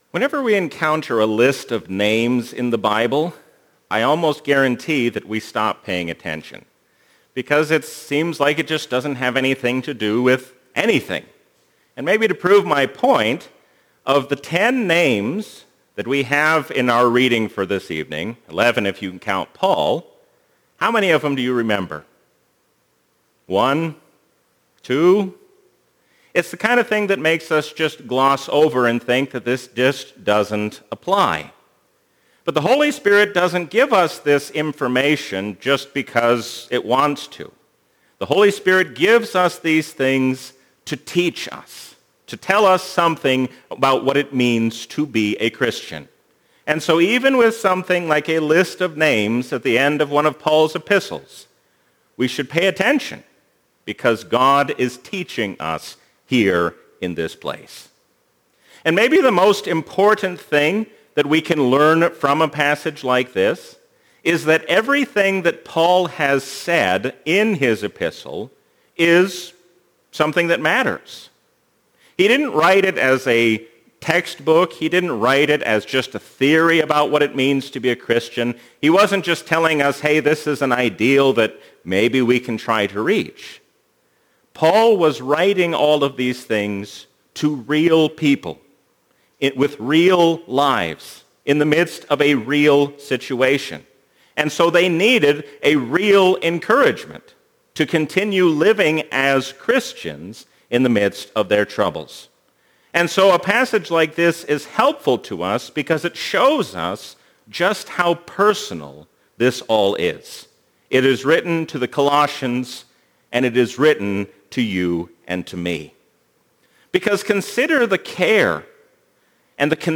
A sermon from the season "Trinity 2021."